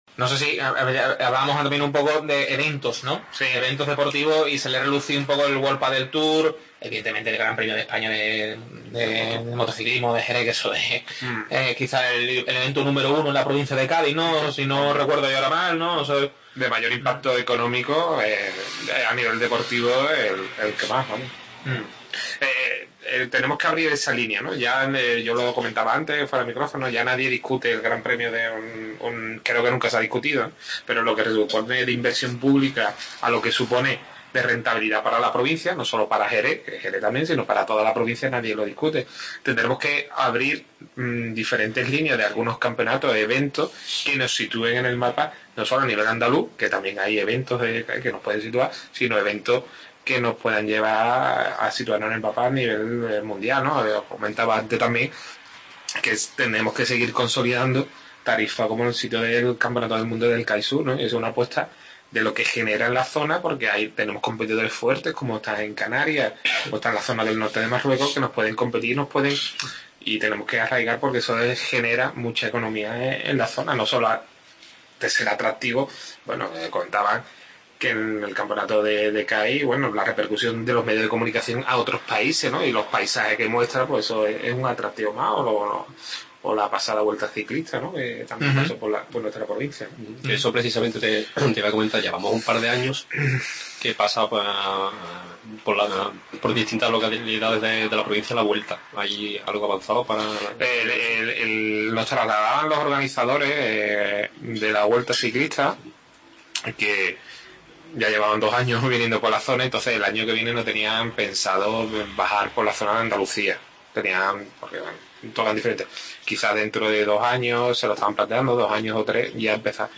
AUDIO: Segunda parte de la tertulia en De Otero. Equipos, clubes, deporte provincial, todo ello y más protagonista hoy